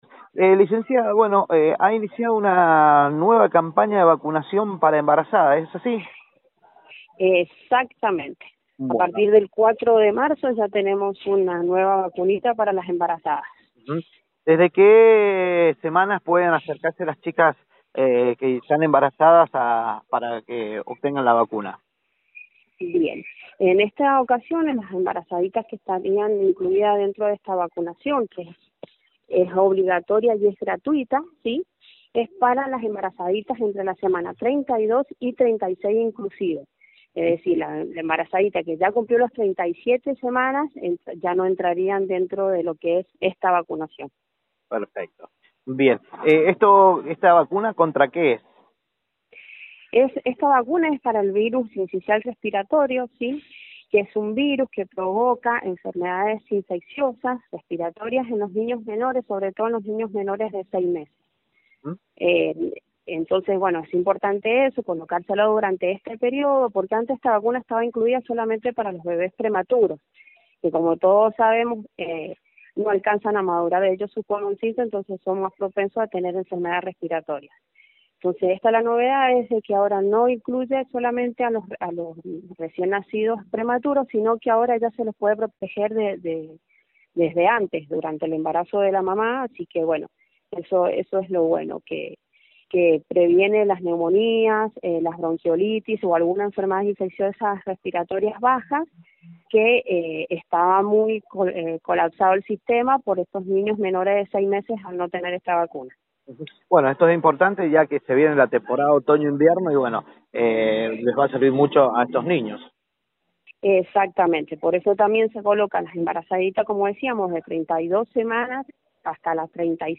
Nota del día: entrevista sobre vacuna para embarazadas.